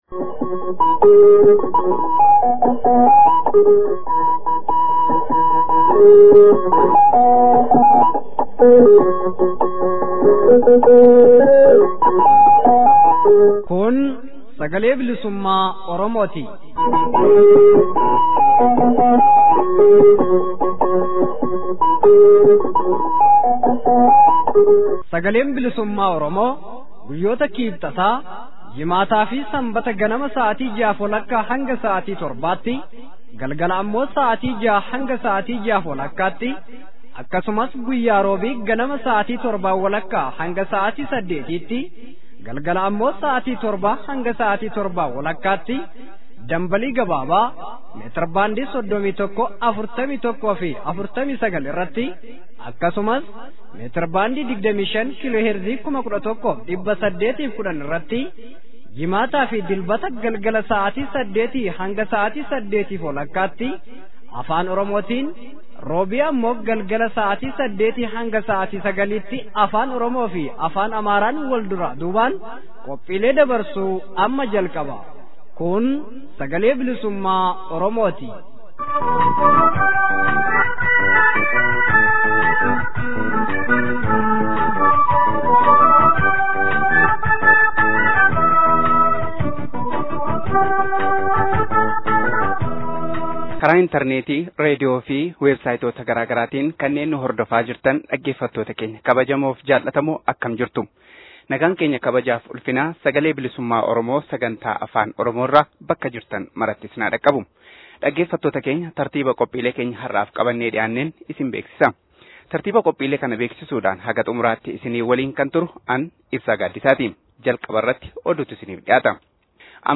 SBO: Amajjii 27 bara 2017. Oduu, Yaada Hirmaattota Sirna Kabajaa Guyyaa WBO biyya Afrikaa-Kibbaatti gaggeeffamee fi Gaaffii fi deebii